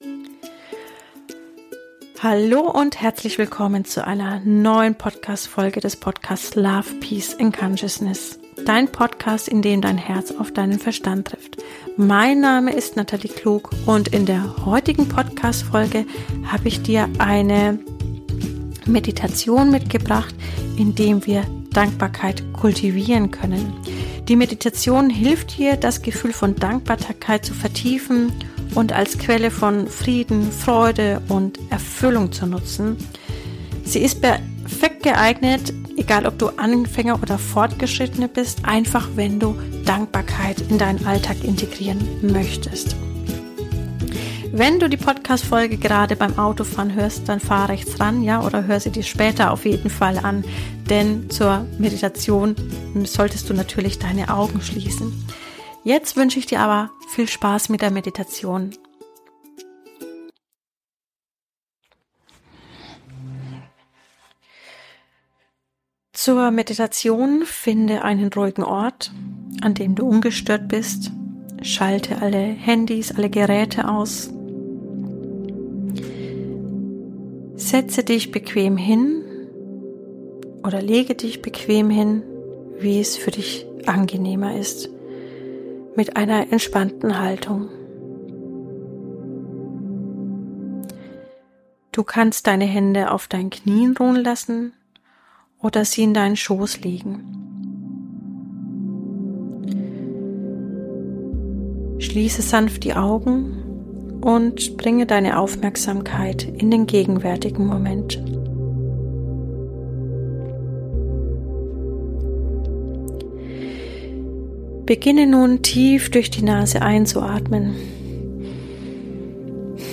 geführte Meditation